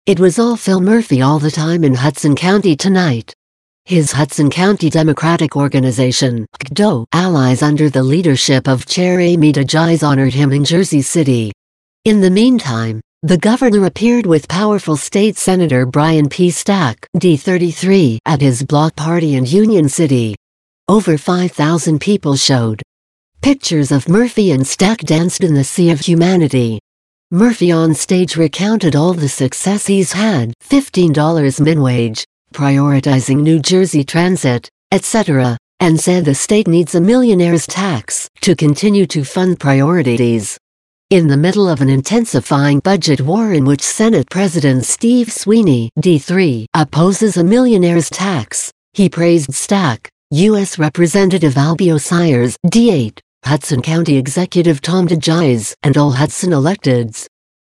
In the meantime, the governor appeared with powerful state Senator Brian P. Stack (D-33) at his block party in Union City.
Murphy onstage recounted all the success he’s had ($15 min wage, prioritizing NJ Transit, etc), and said the state needs a millionaire’s tax to continue to fund priorities.
Stack-Murphy-Block-aprty.mp3